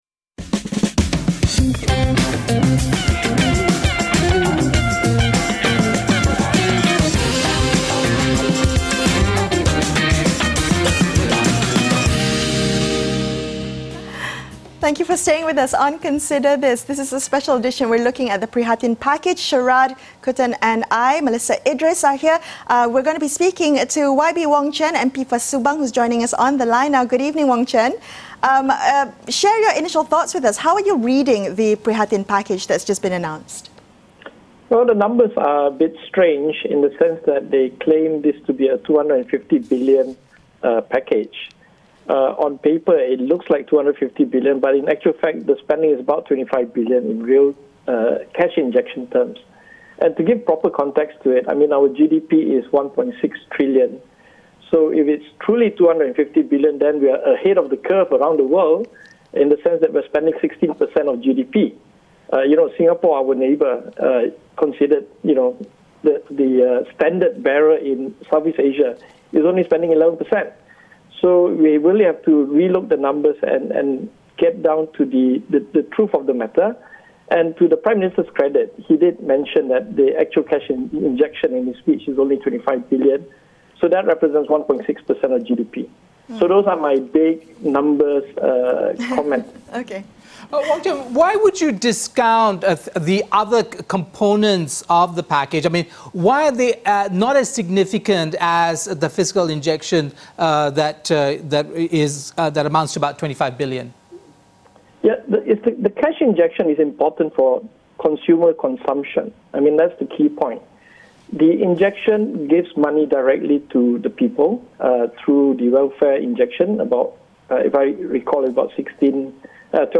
speak to Wong Chen, PKR Member of Parliament for Subang for his personal take on the